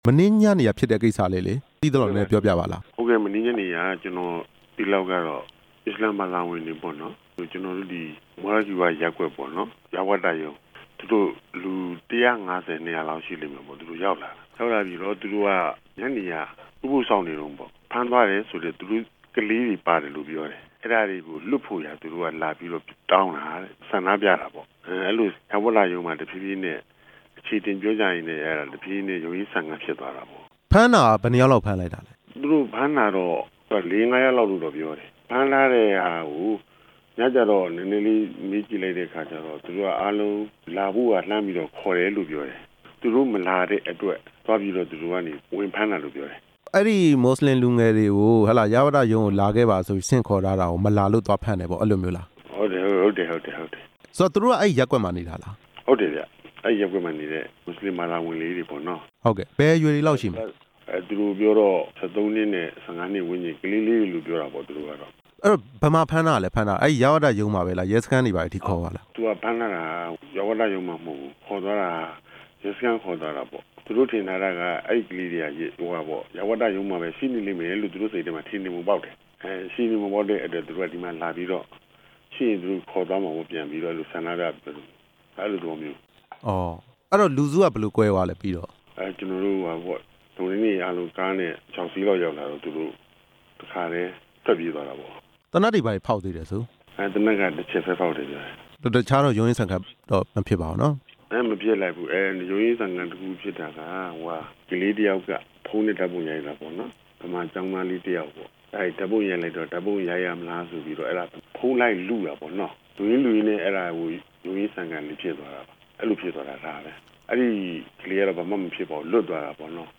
ဆက်သွယ်မေးမြန်းထားတာကို နားဆင်နိုင်ပါတယ်။